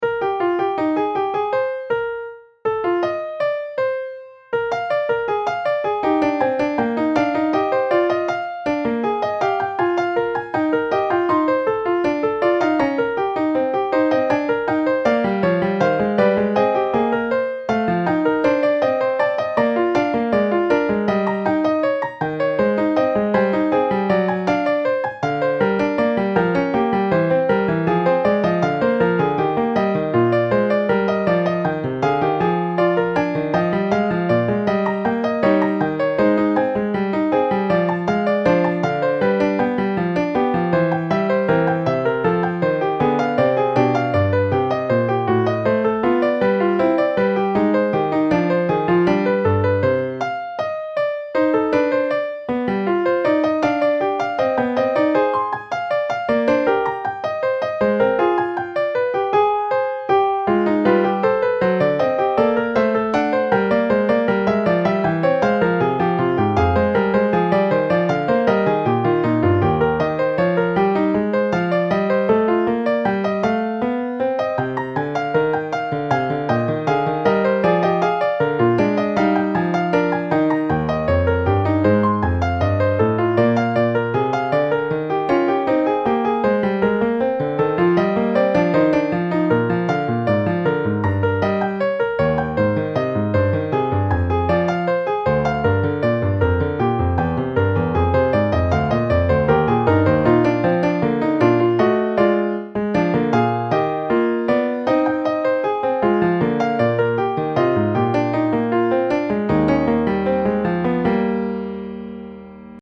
Listen to the Fugue in E-Flat Major here: You can download this as an MP3 here .
Fugue in E Flat Major.mp3